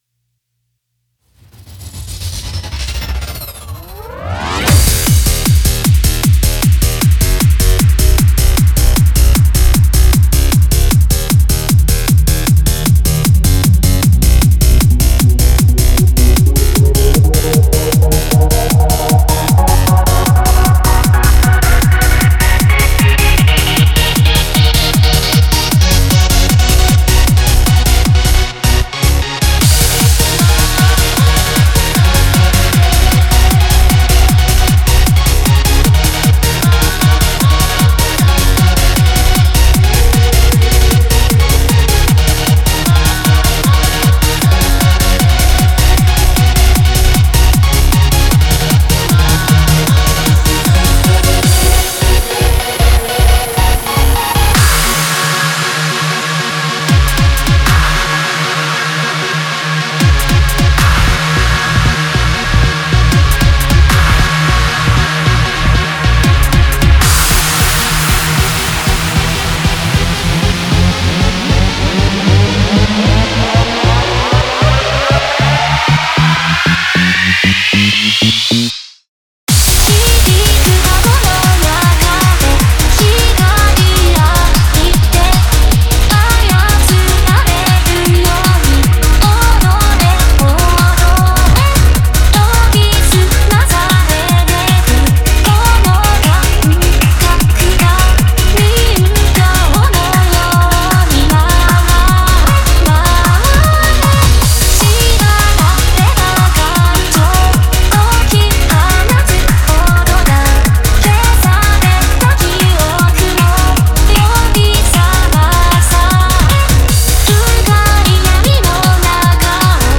EDM、トランス楽曲